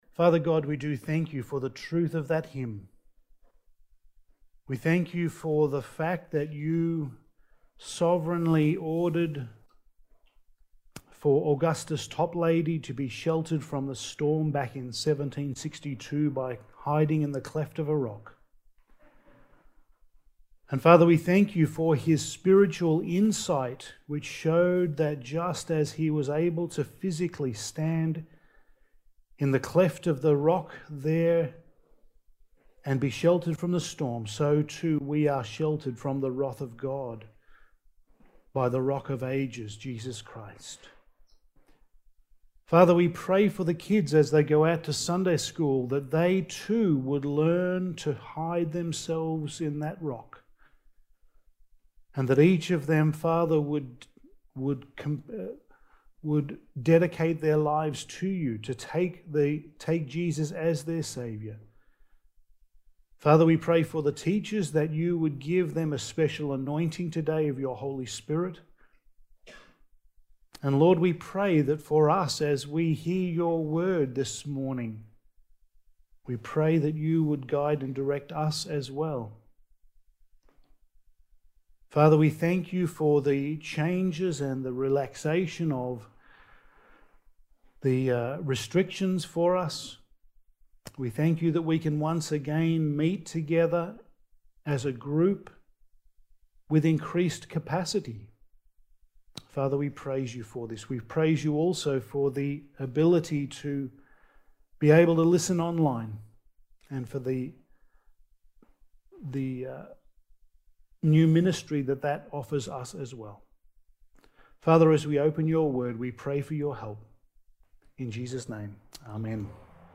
Passage: Acts 19:8-20 Service Type: Sunday Morning